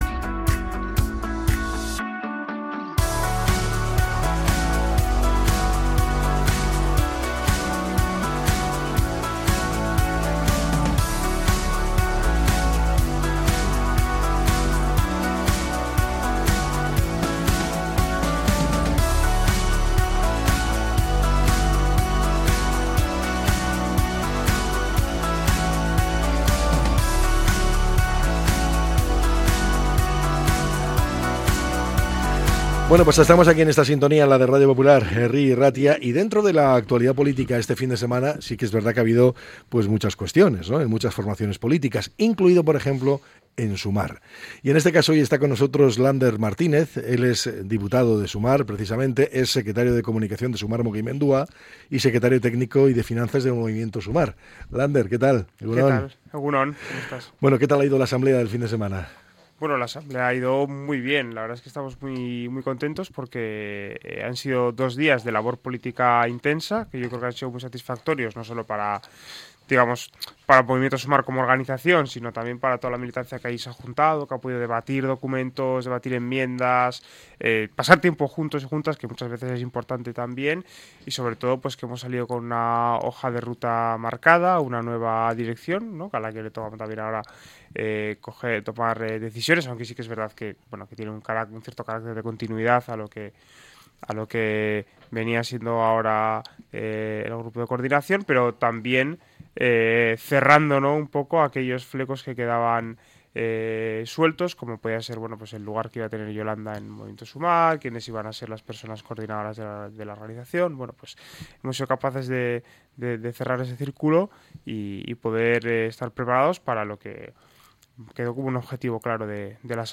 El diputado de Sumar ha compartido en Radio Popular - Herri Irratia las claves de la asamblea celebrada este fin de semana